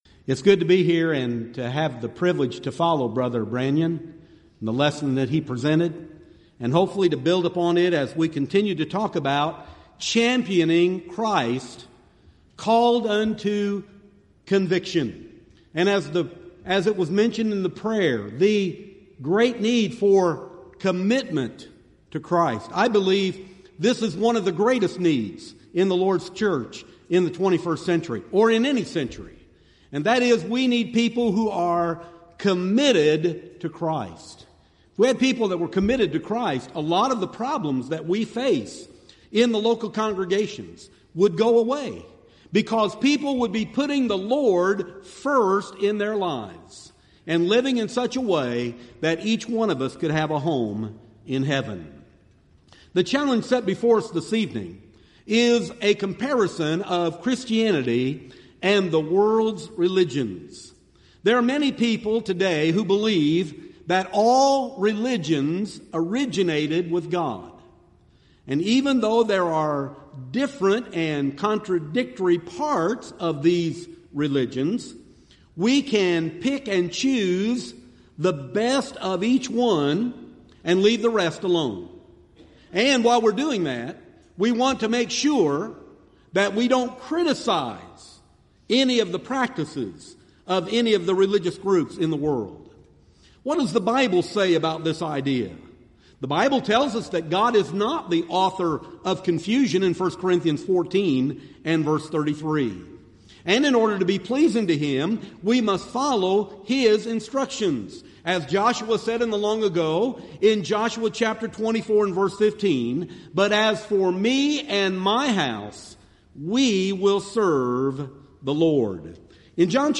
Series: Southwest Lectures Event: 30th Annual Southwest Bible Lectures